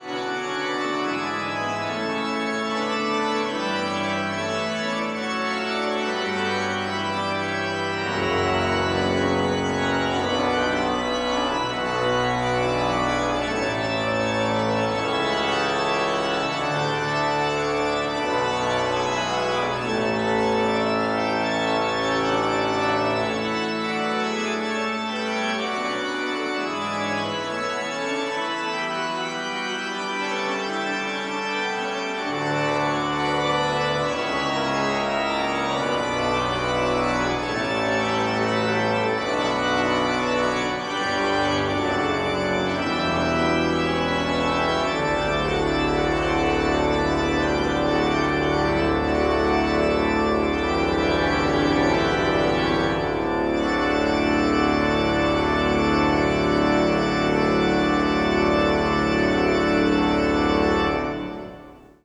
Tetrahedral Ambisonic Microphone
Organ Recital
Recorded December 12, 2009, in the Bates Recital Hall at the Butler School of Music of the University of Texas at Austin.